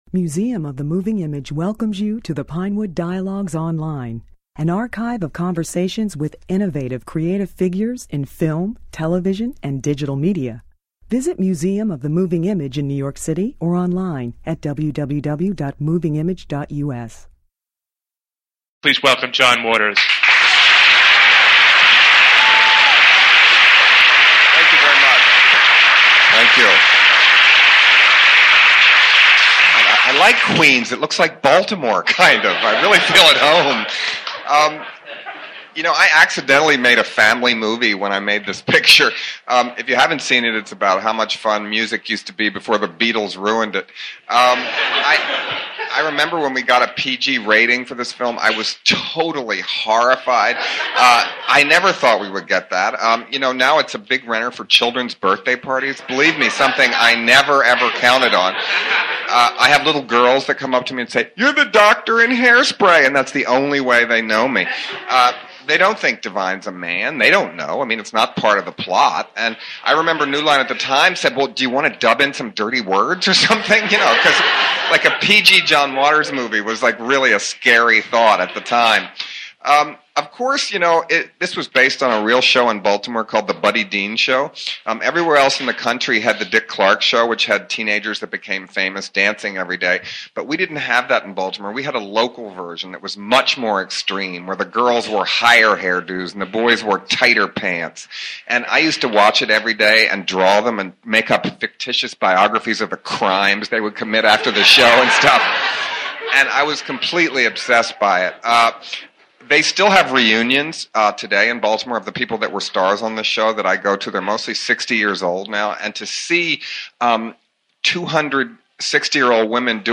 Waters spoke about Hairspray as part of a Moving Image retrospective of his films.